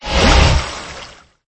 128-Water03.mp3